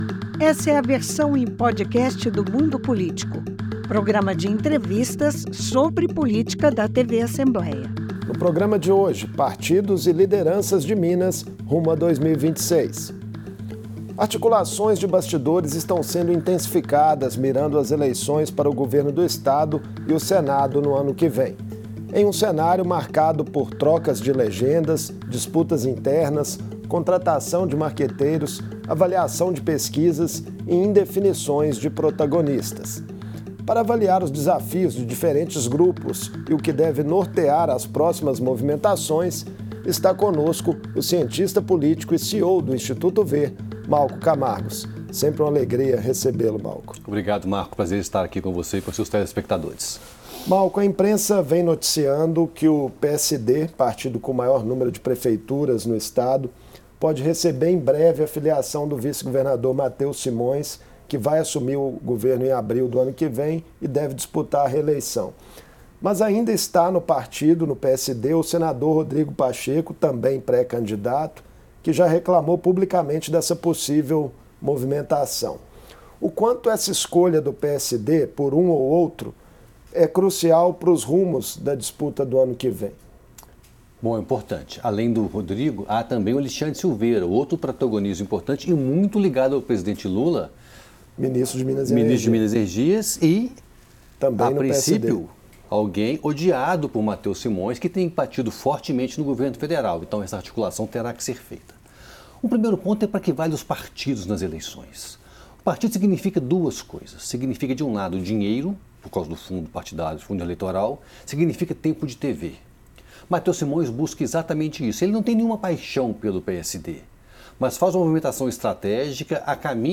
A pouco mais de um ano das eleições, partidos e pré-candidatos aumentam o ritmo de articulações nos bastidores da política, de olho nas eleições ao governo de Minas. Em entrevista